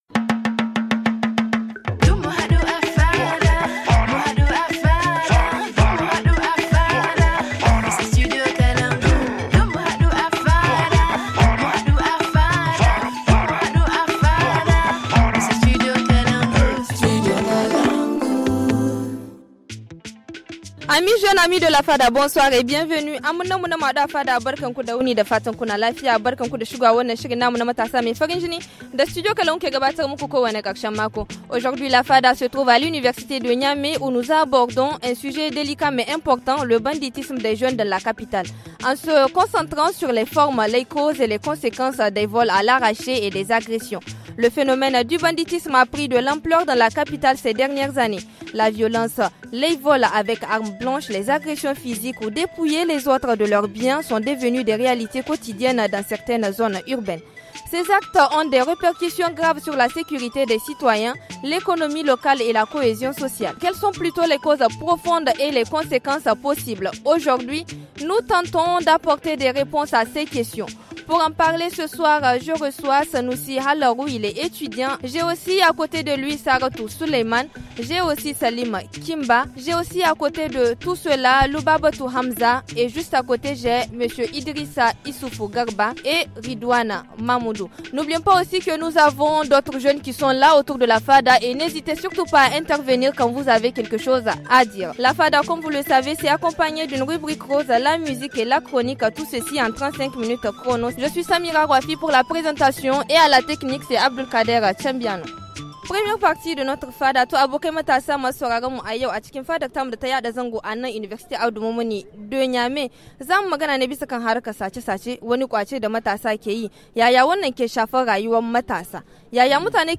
Aujourd’hui, la fada se retrouve à l’université de Niamey où nous allons aborder un sujet délicat mais important : le banditisme des jeunes dans la capitale, en se concentrant sur les formes, les causes et les conséquences des vols à l’arraché et des agressions.